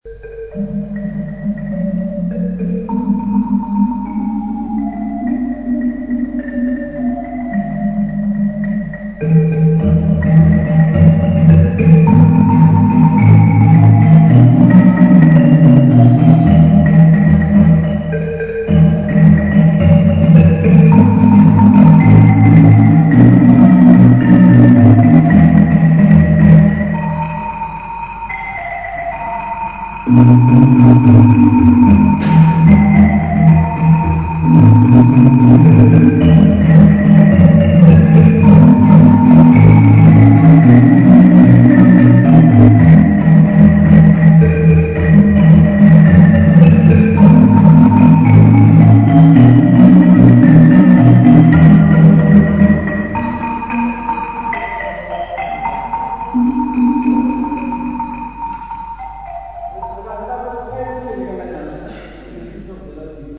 DD104-01.mp3 of Marimbas Song